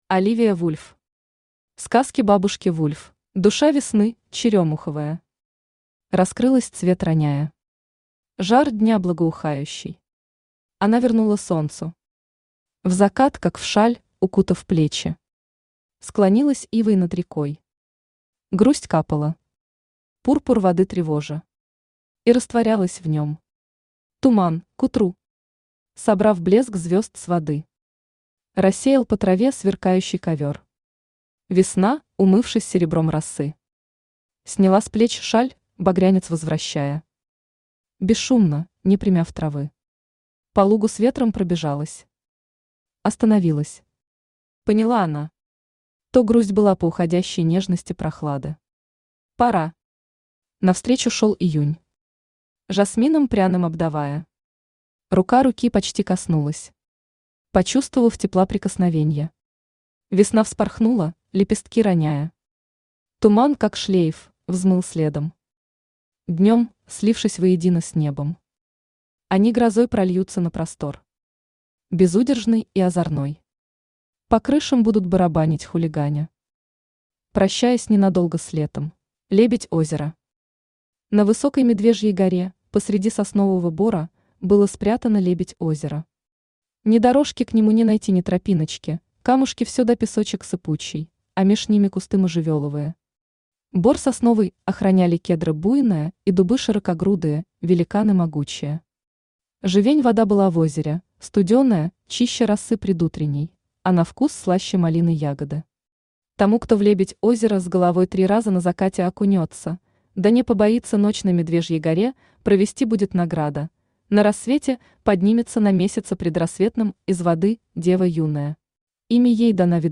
Аудиокнига Сказки бабушки Вульф | Библиотека аудиокниг
Aудиокнига Сказки бабушки Вульф Автор Оливия Вульф Читает аудиокнигу Авточтец ЛитРес.